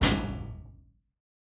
PlayerRocketImpact.wav